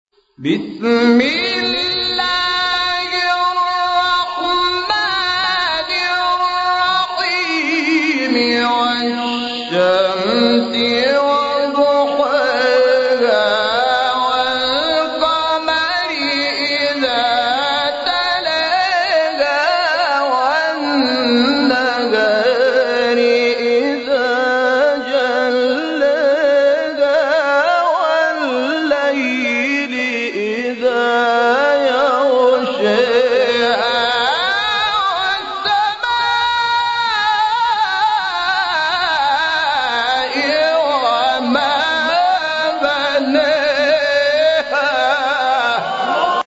/ فیلم برچسب‌ها: تلاوت قرآن محمود شحات انور سوره شمس دیدگاه‌ها (اولین دیدگاه را بنویسید) برای ارسال دیدگاه وارد شوید.